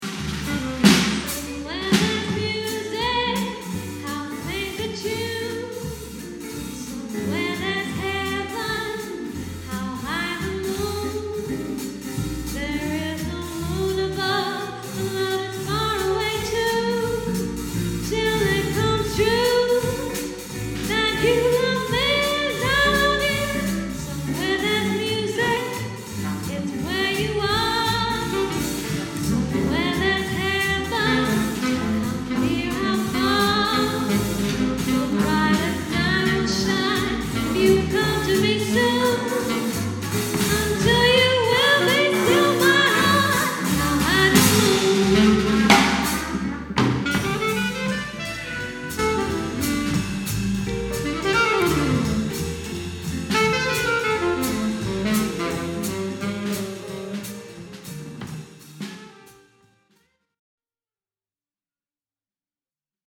Jazz Bands
Duo jazz to Big Bands